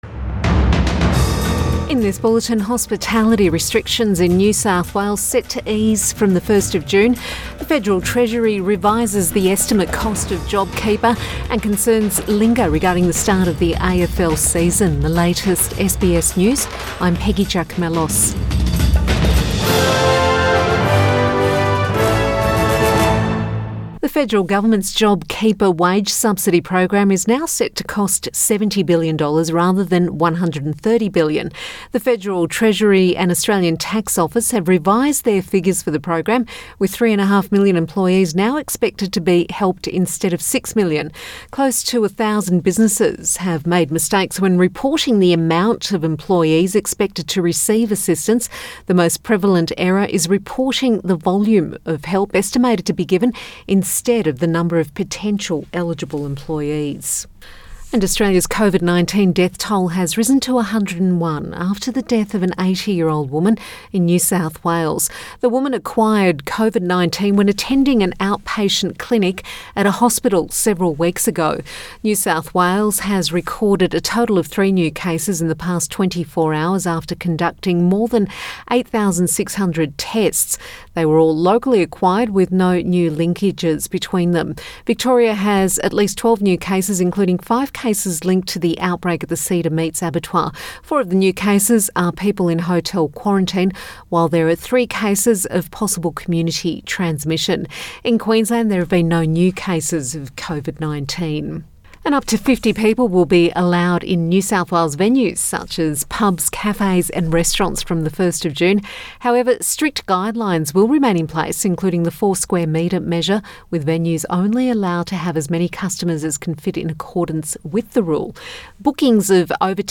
PM bulletin May 22 2020